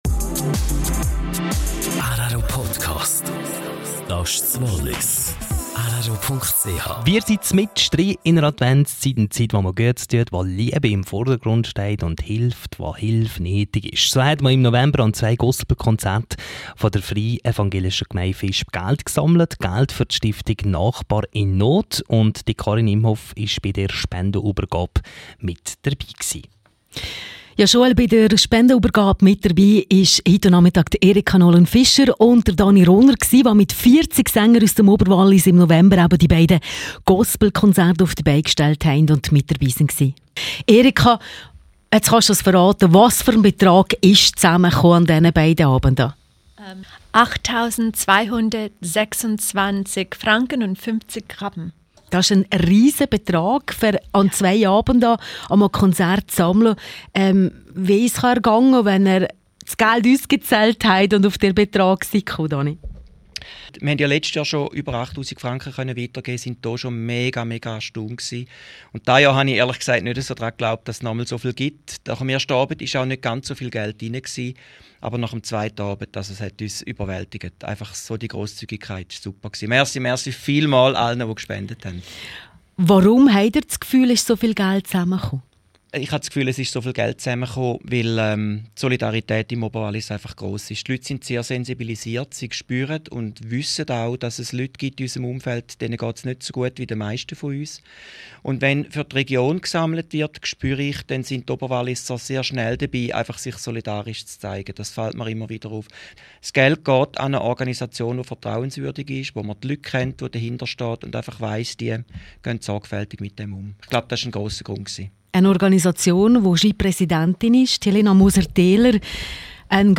17:00 Uhr Nachrichten (3.43MB)